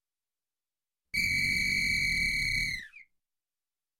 Звуки полицейского свистка
Гаишник насвистывает